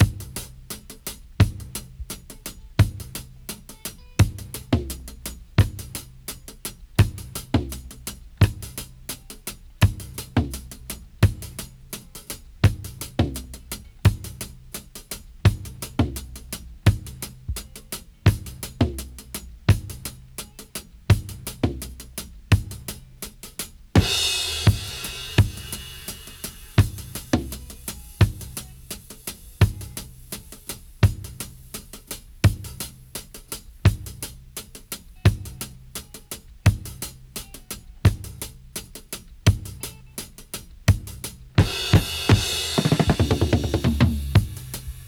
85-DRY-04.wav